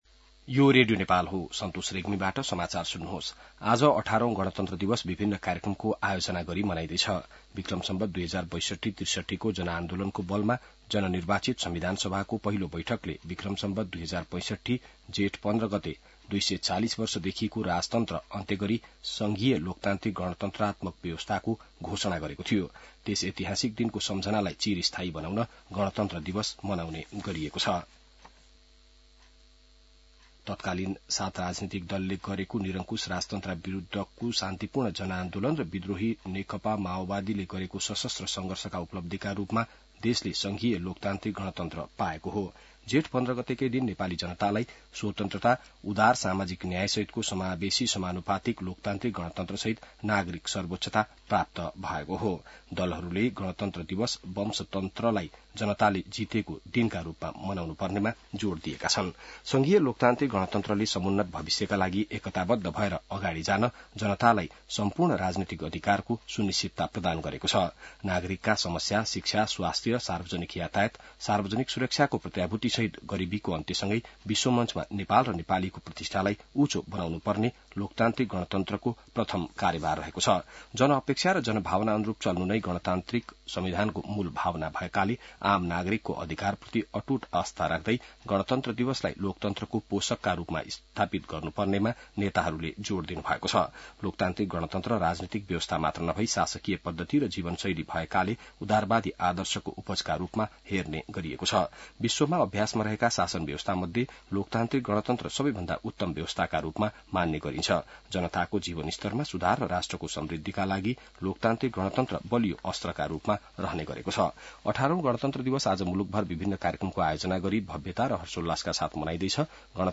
बिहान ६ बजेको नेपाली समाचार : १५ जेठ , २०८२